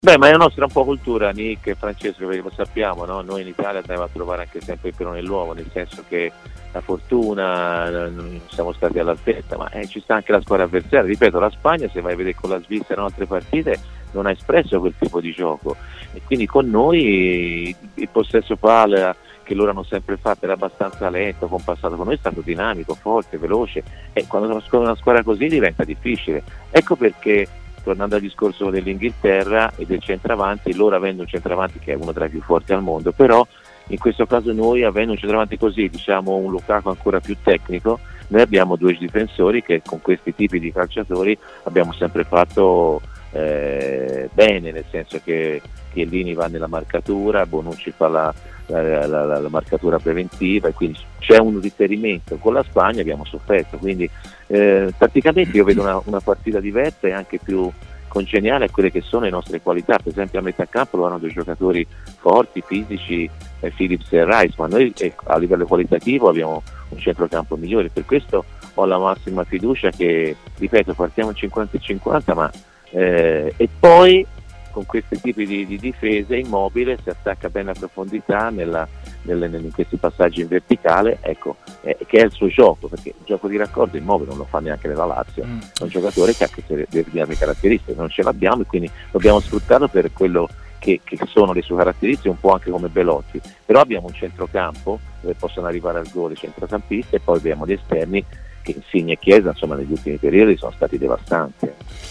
Intervenuto ai microfoni di TMW Radio, Antonio Di Gennaro ha parlato anche di Immobile e delle critiche ricevute dopo le prove con la Nazionale: "Fanno parte della nostra cultura.